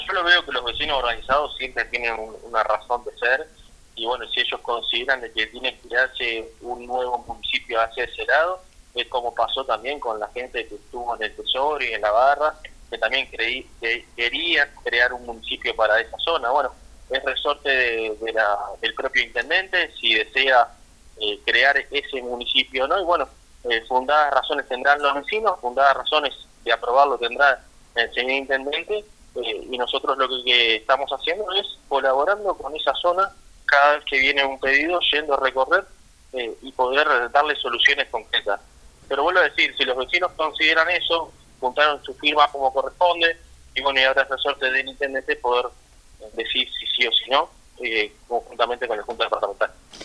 El alcalde, Damián Tort, dijo respetar el deseo de los vecinos y espera por la decisión del intendente y los ediles
Informe